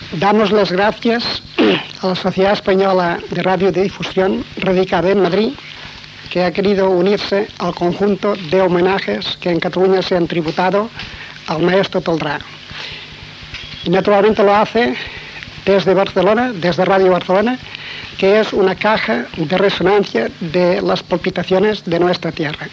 Paraules de l'empresari i mecenes Lluís Carulla en l'homenatge al mestre Eduard Toldrà que va transmetre la Cadena SER.